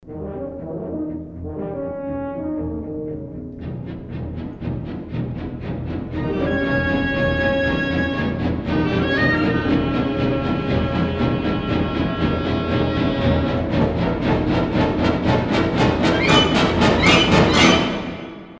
инструментальные
пугающие , жуткие
нарастающие , страшные